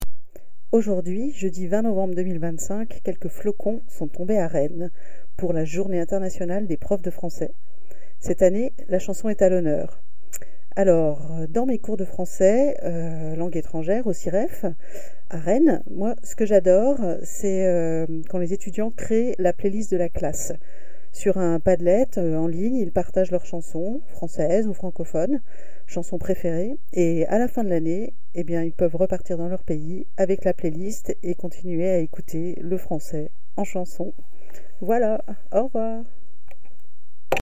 Cabine de témoignages
Témoignage du 20 novembre 2025 à 18h12